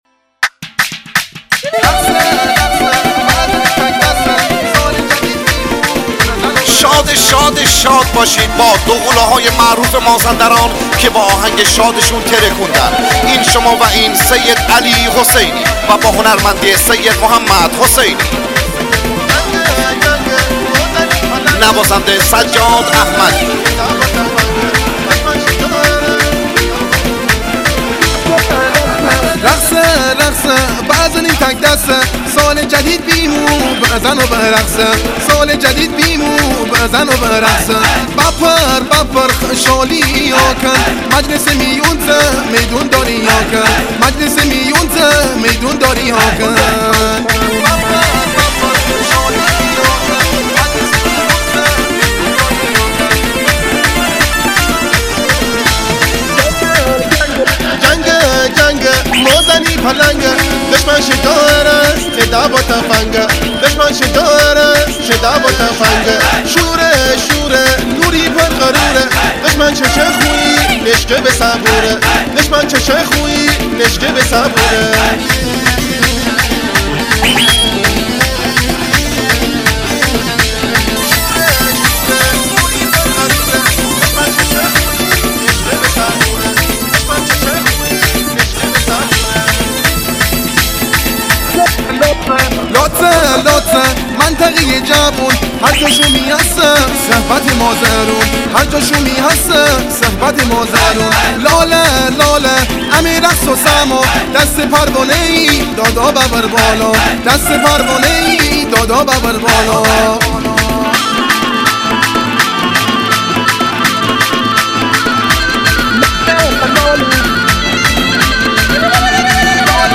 مجموعه آهنگهای شاد مخصوص برای عروسی سال ۲۰۱۹